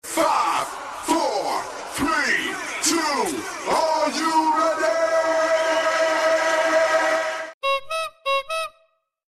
start countdown Meme Sound Effect
start countdown.mp3